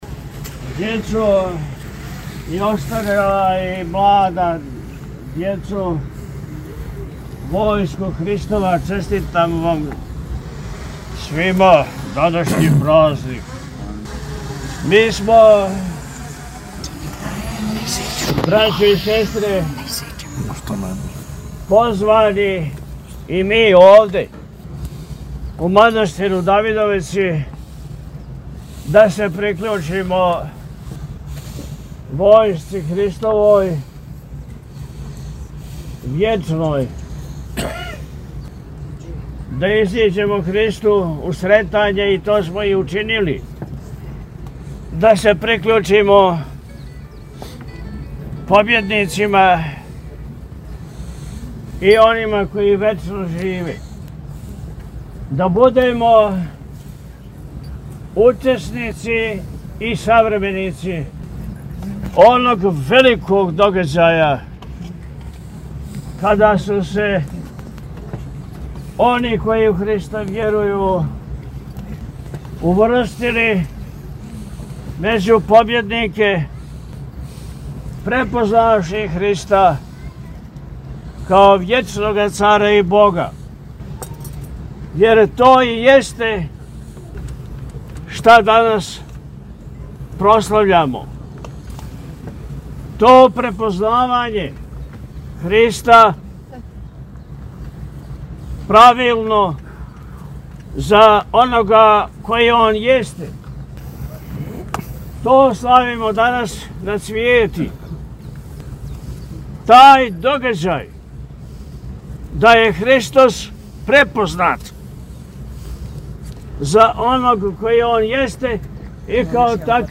Традиционално, празник Улазак Господа Исуса Христа у Јерусалим – Цвети свечано се прославља у манастиру Давидовица код Бродарева.